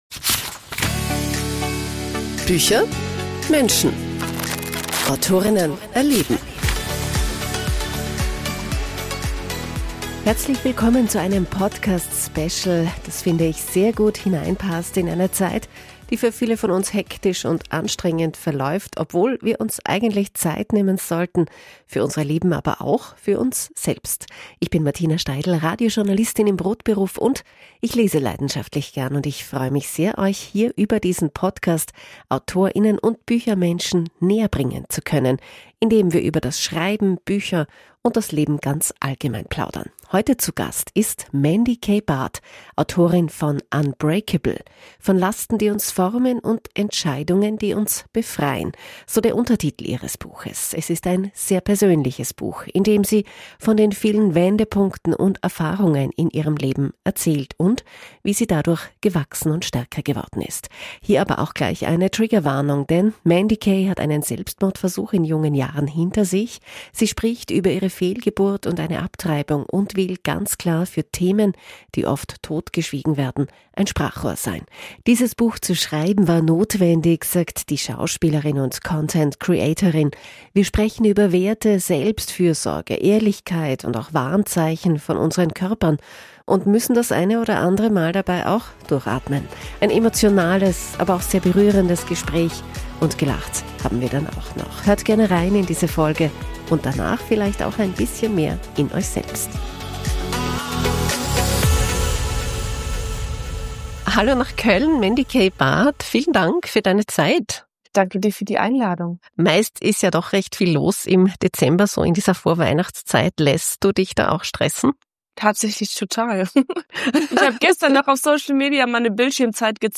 ein emotionales aber sehr berührendes Gespräch – gelacht haben wir aber auch.